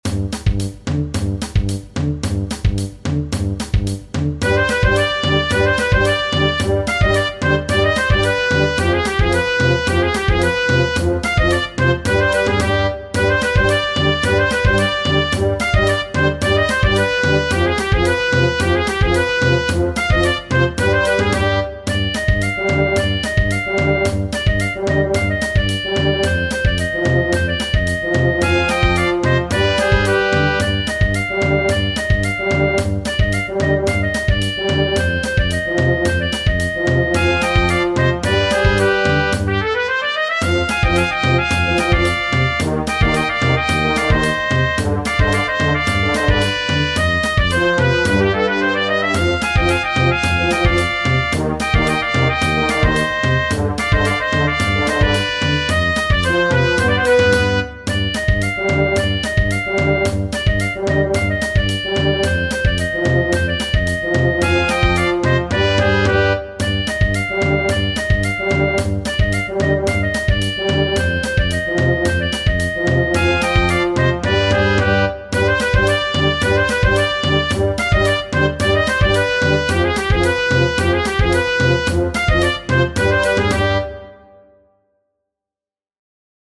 Tradizionale Genere: Folk "Lag Bari", che in italiano significa "grande fuoco", è una popolare canzone originaria della Romania e diffusa anche in Spagna con il titolo "Que dolor!". Lag Bari (Que dolor!)